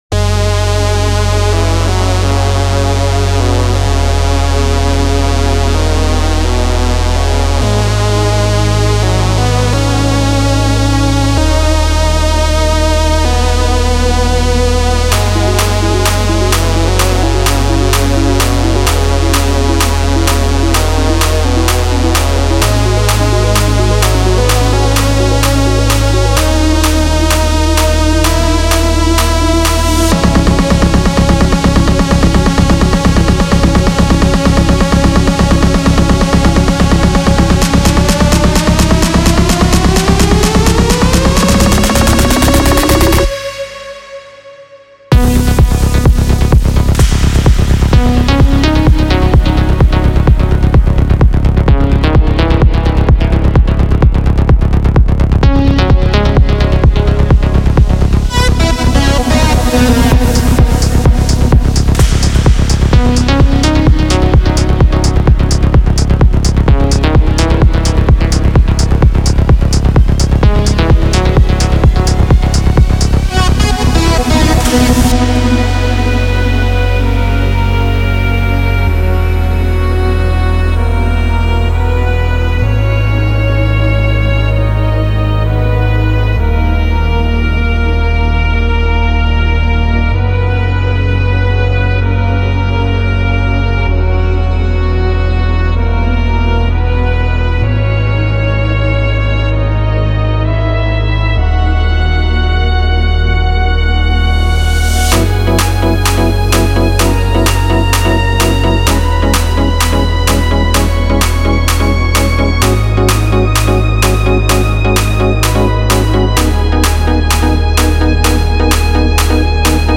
EDM(ダンスミュージック)というジャンルの曲なのですが、YouTubeやこのブログでも紹介しています。